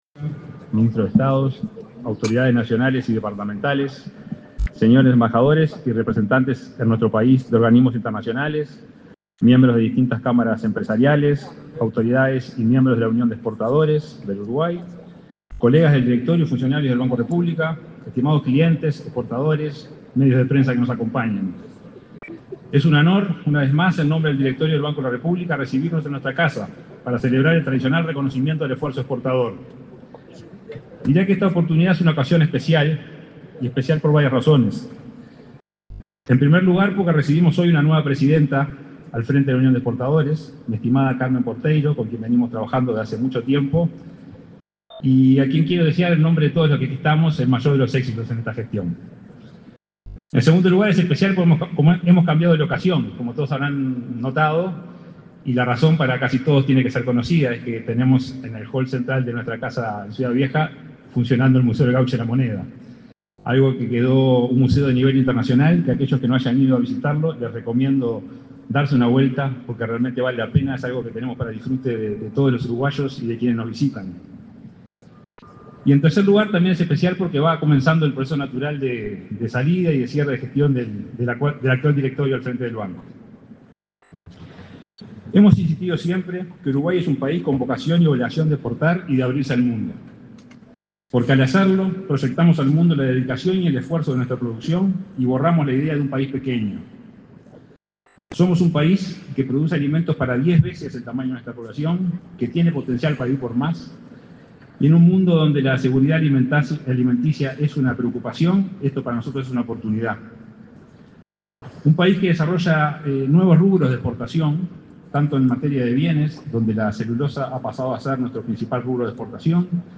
Palabras del presidente del Banco República, Salvador Ferrer
El presidente de la República, Luis Lacalle Pou, participó, este 4 de diciembre, en la ceremonia de Reconocimiento al Esfuerzo Exportador 2024.
El presidente del Banco República, Salvador Ferrer, realizó declaraciones en el evento.